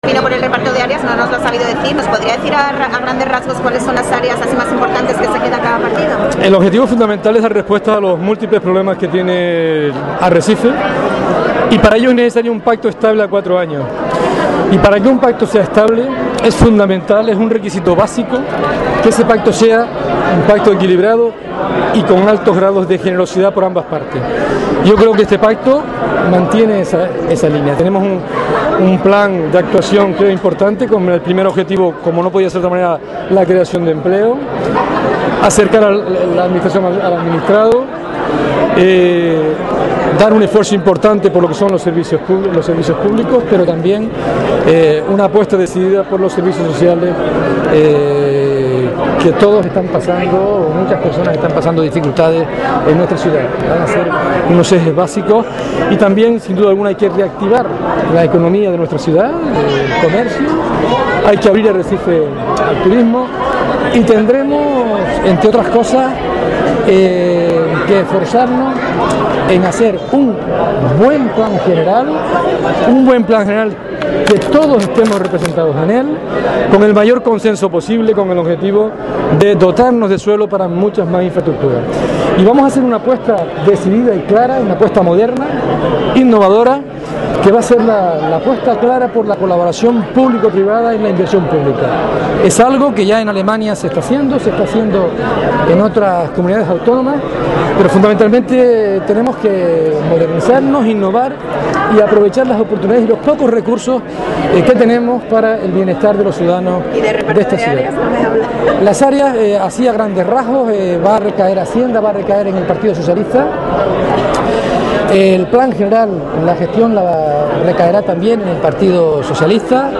Escuche aquí las declaraciones de Reguera, Montelongo, Fajardo Feo y Espino tras el pleno de Arrecife